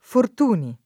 vai all'elenco alfabetico delle voci ingrandisci il carattere 100% rimpicciolisci il carattere stampa invia tramite posta elettronica codividi su Facebook Fortuny [cat. furt 2 n’ ; sp. fort 2 ni ; it. f ort 2 ni ] cogn.